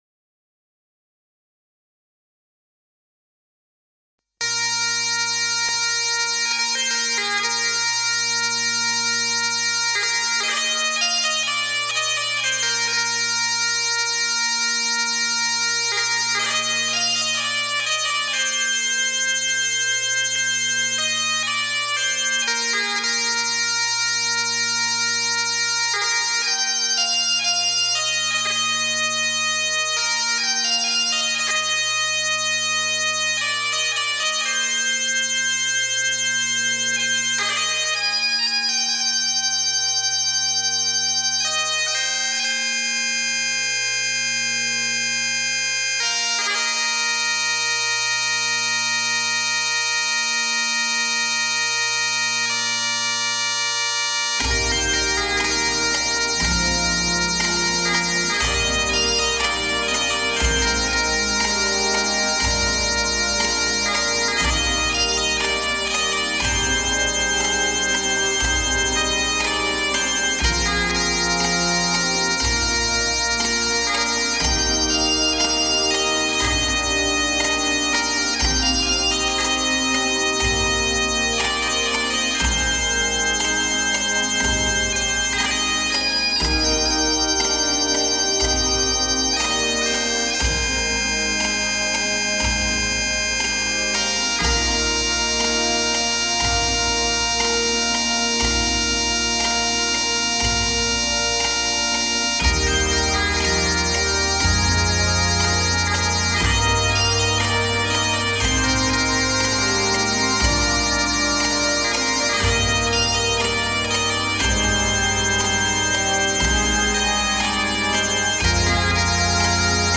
Piccolo
Euphonium
Drum Kit
Bass Guitar